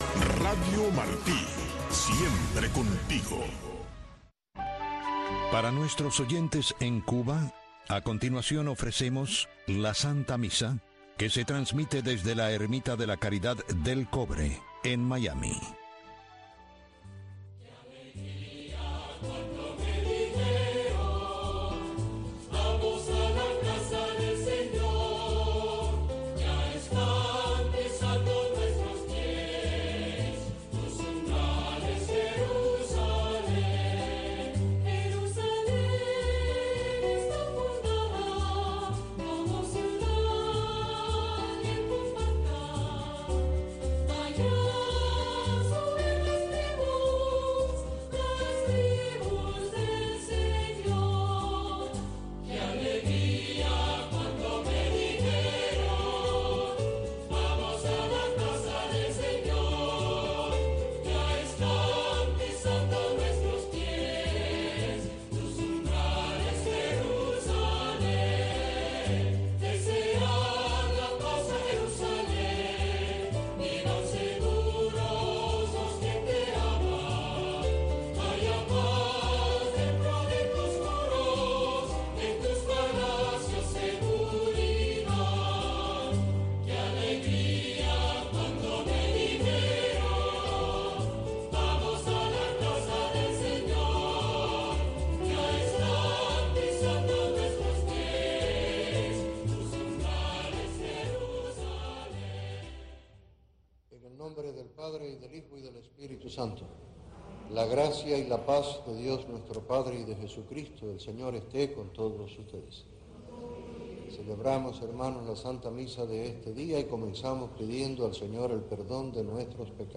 La Santa Misa
PROGRAMACIÓN EN-VIVO DESDE LA ERMITA DE LA CARIDAD